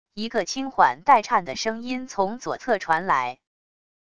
一个轻缓带颤的声音从左侧传来wav音频